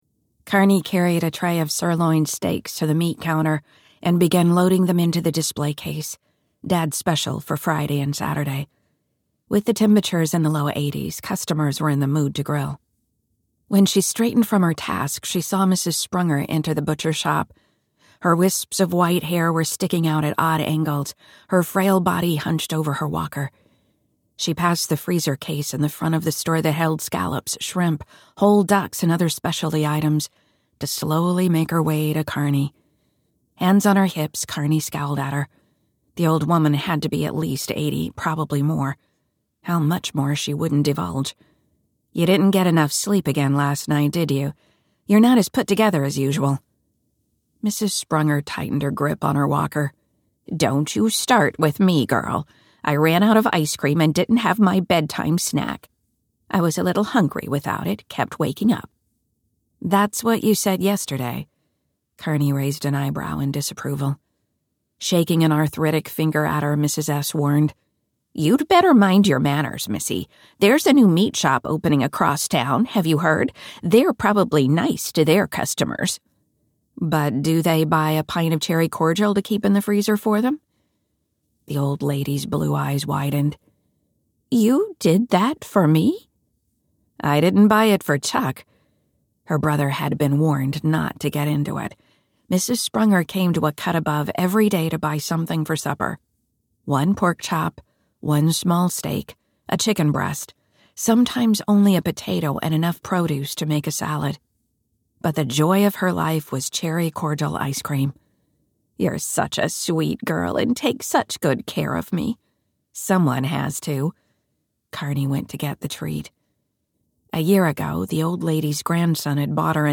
A Cut Above - A Karnie Cleaver Mystery, Book One - Vibrance Press Audiobooks - Vibrance Press Audiobooks